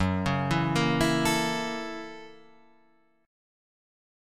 Gb7#9 Chord